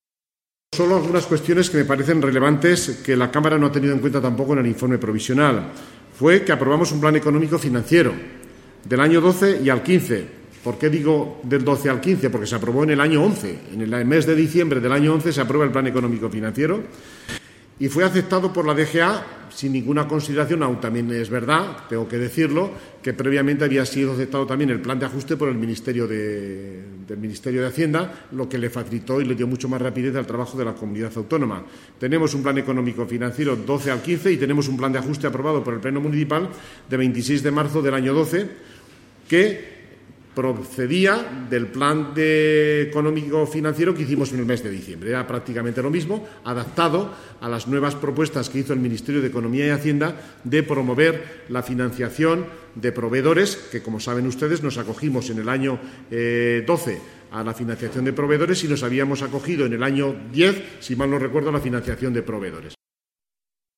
El vicealcalde, Fernando gimeno, ha manifestado al respecto: